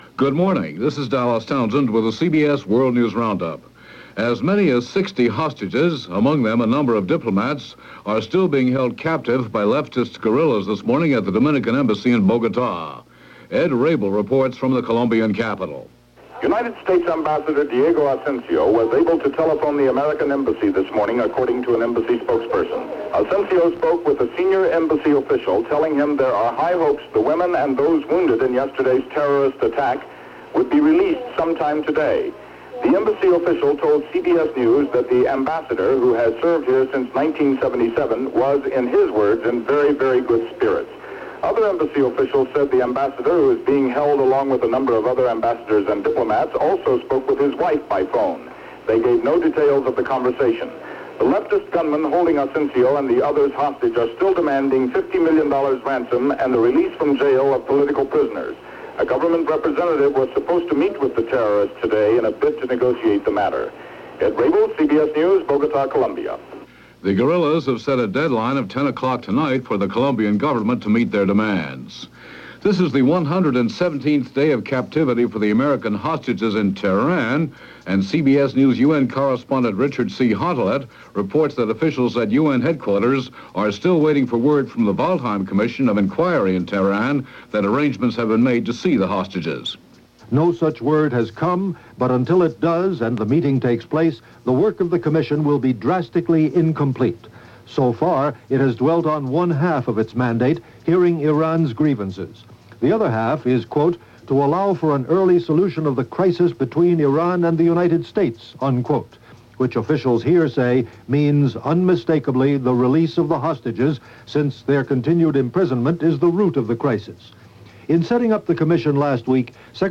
CBS World News Roundup -The World Tonight – Hourly News